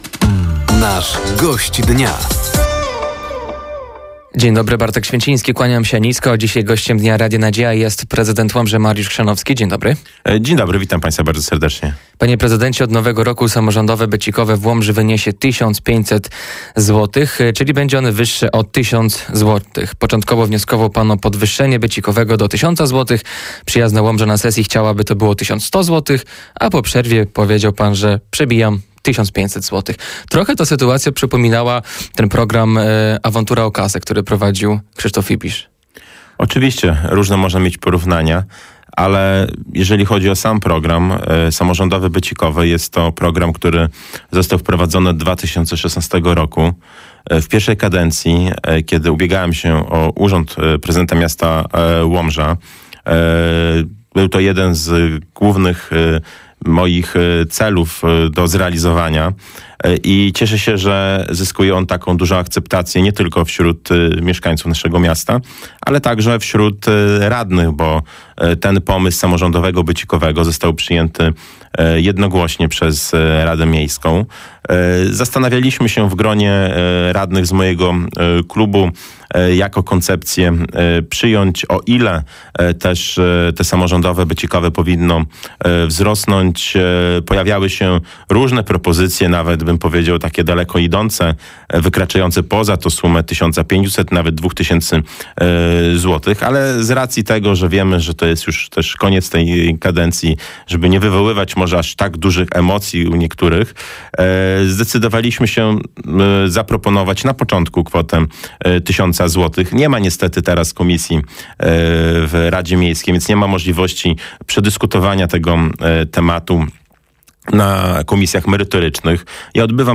Gościem Dnia Radia Nadzieja był prezydent Łomży, Mariusz Chrzanowski. Tematem rozmowy była między innymi budowa Mediateki – Książnicy Łomżyńskiej, samorządowe becikowe oraz Karta Mieszkańca Łomży